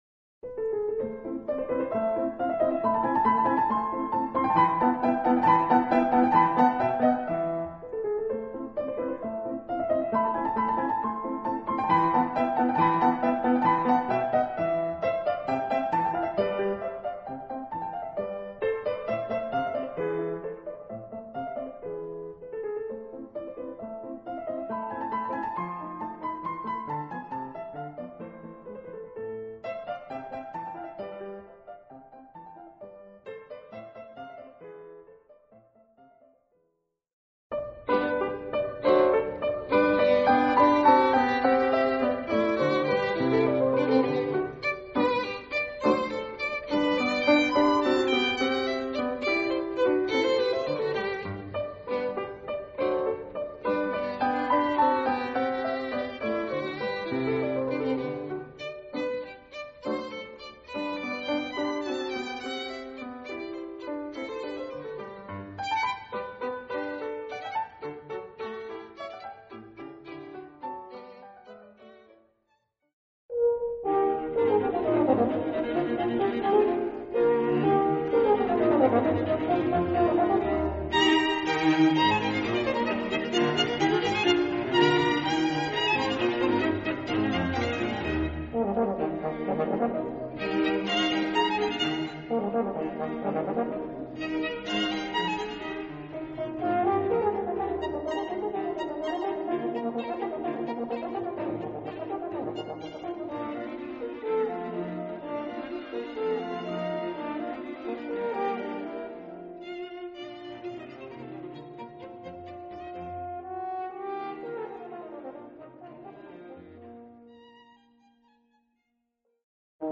sonatas, concerts, and minuets